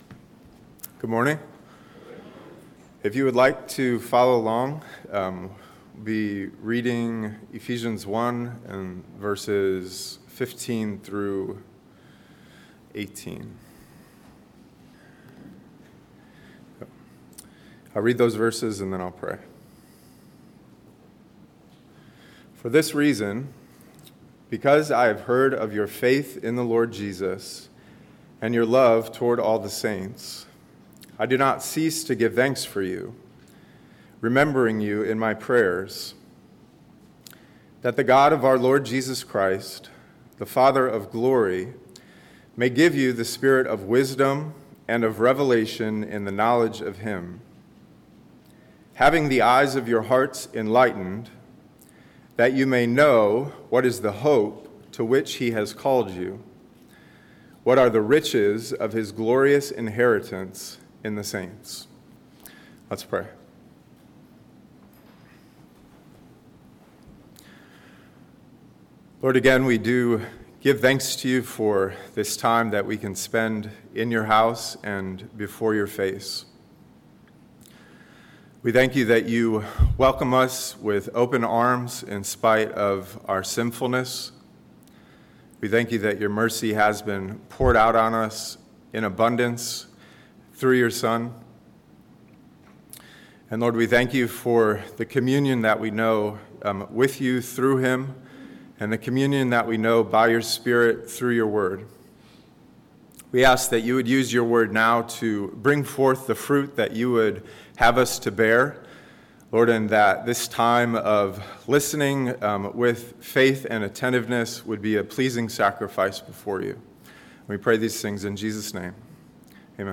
A message from the series "Ephesians 2025."